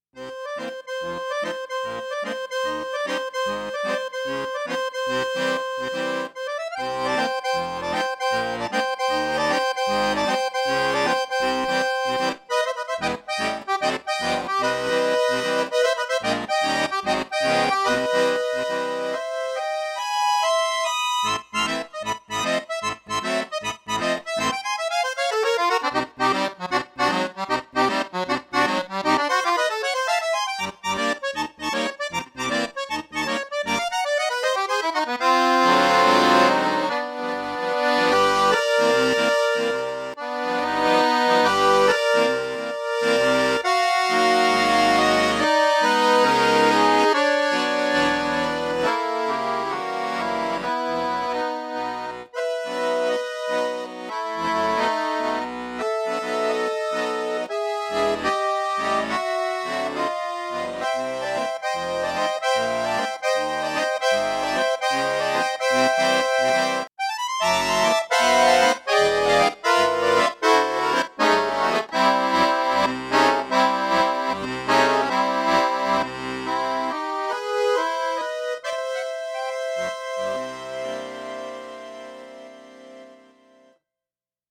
Solo performances